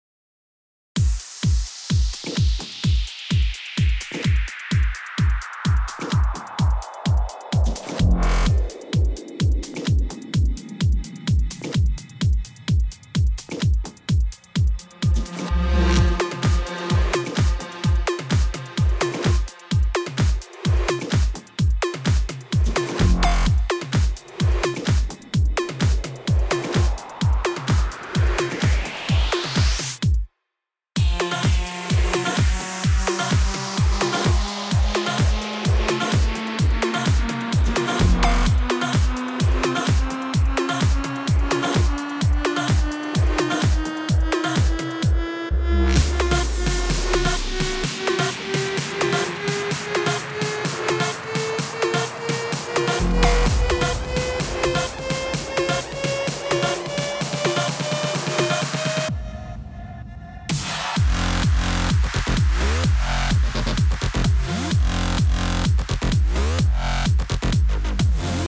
Нажмите для раскрытия... ок, вот файл, тот нарастающий питч с 30-й секунды Вложения mix_cut.mp3 mix_cut.mp3 2,6 MB · Просмотры: 505